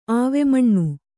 ♪ āvemaṇṇu